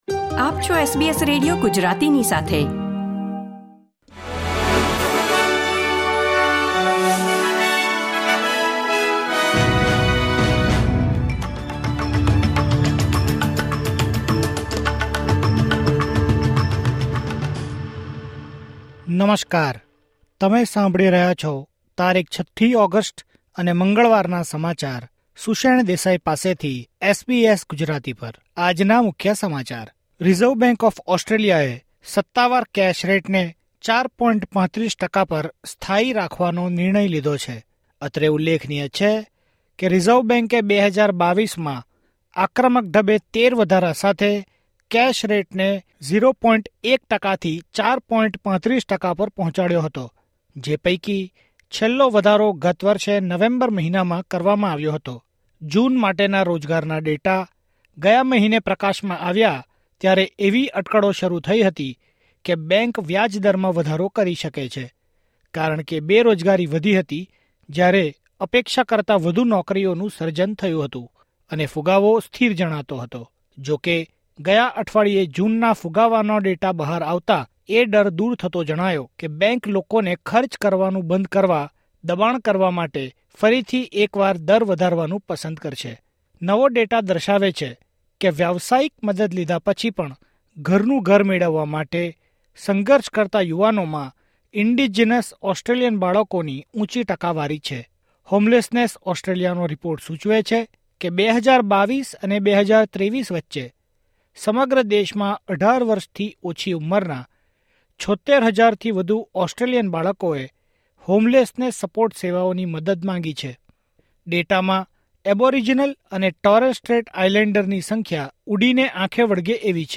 SBS Gujarati News Bulletin 6 August 2024